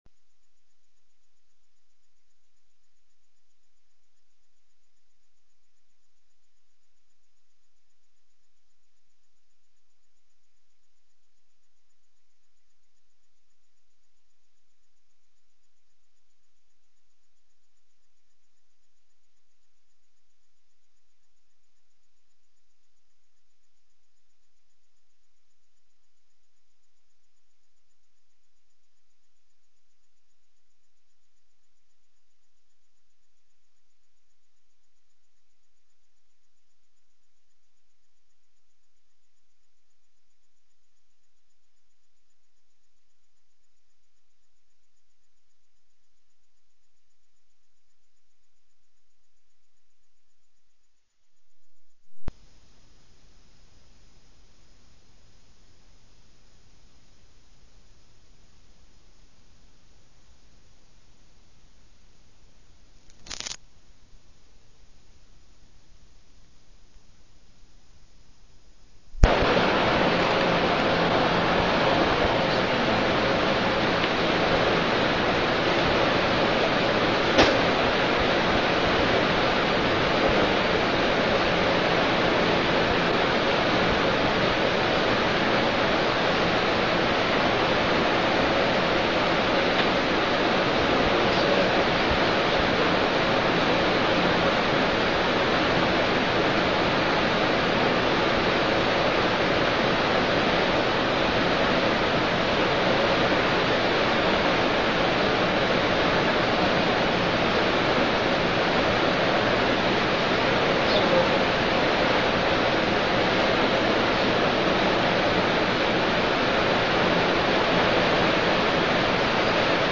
تاريخ النشر ١٤ محرم ١٤٠٩ هـ المكان: المسجد الحرام الشيخ: محمد بن عبد الله السبيل محمد بن عبد الله السبيل الهجرة النبوية